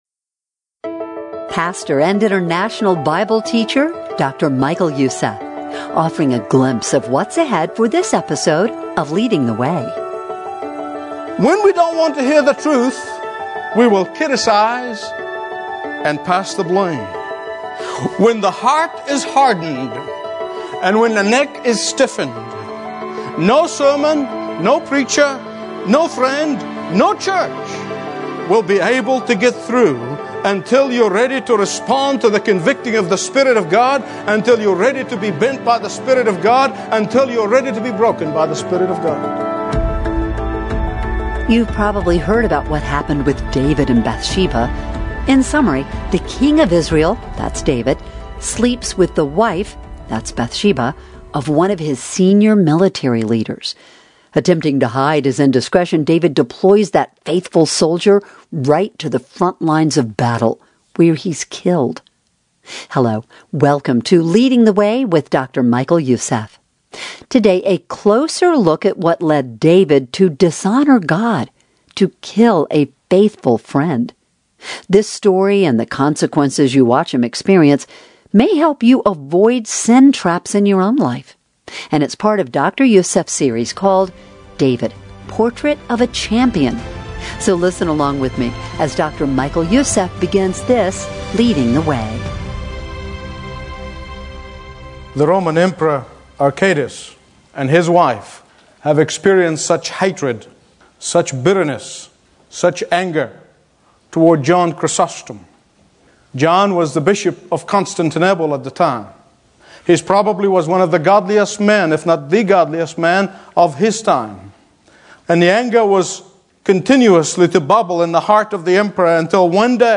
Stream Expository Bible Teaching & Understand the Bible Like Never Before